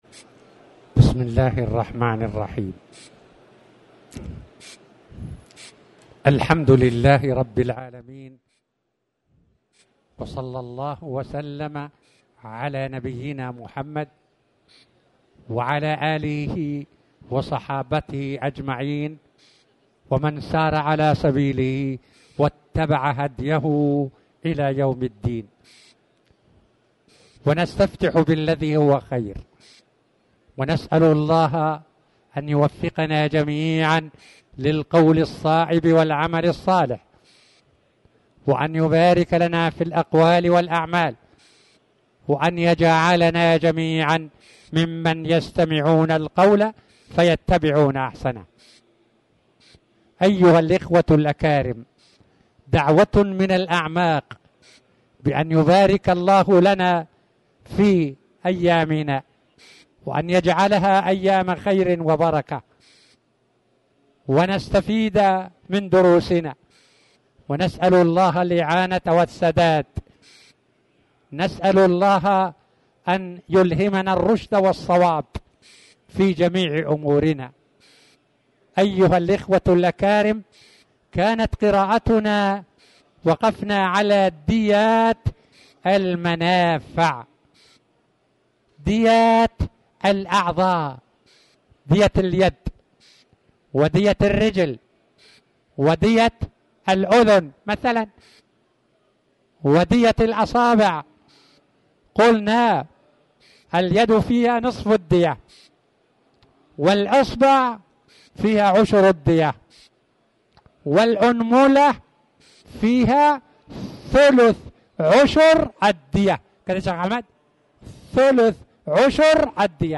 تاريخ النشر ٣٠ ذو الحجة ١٤٣٩ هـ المكان: المسجد الحرام الشيخ